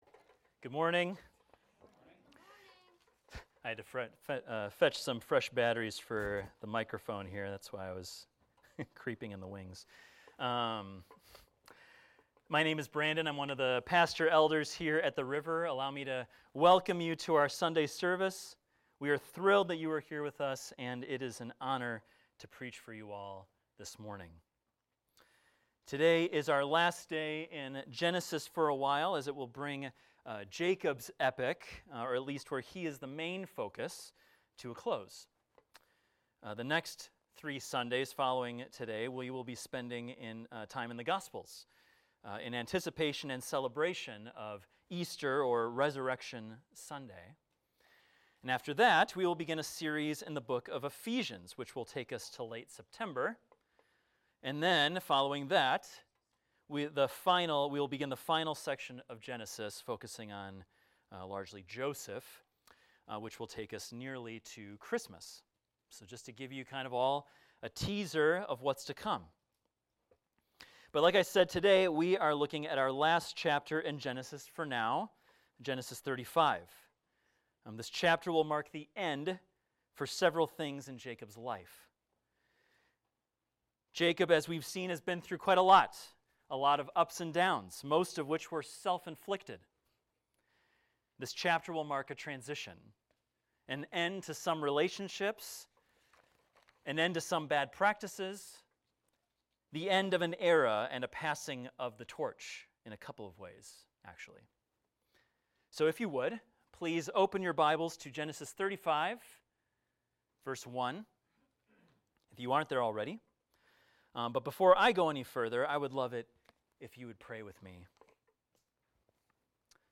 This is a recording of a sermon titled, "The End of an Era."